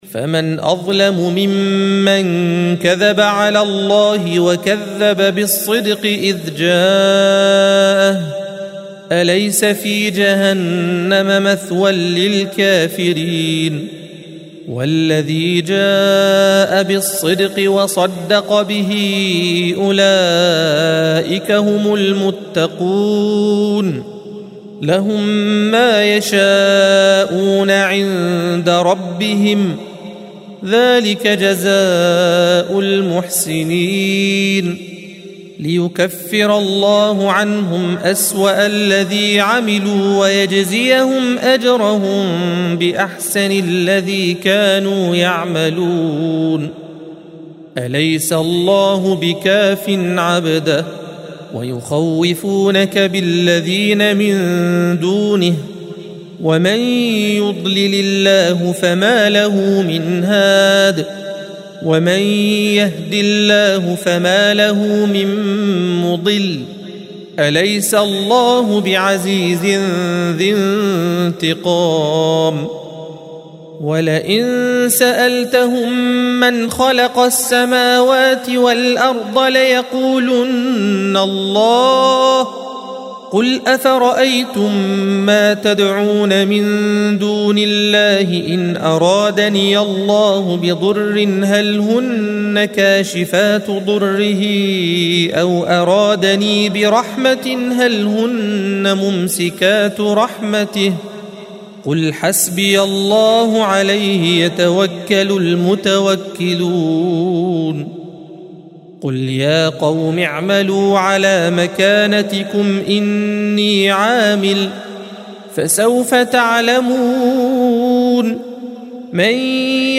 الصفحة 462 - القارئ